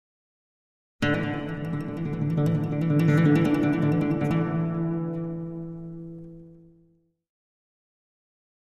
Acoustic Guitar - Guitar Long Legato 1